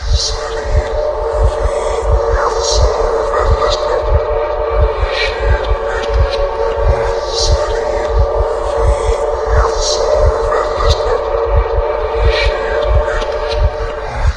psy_voices_1_r.ogg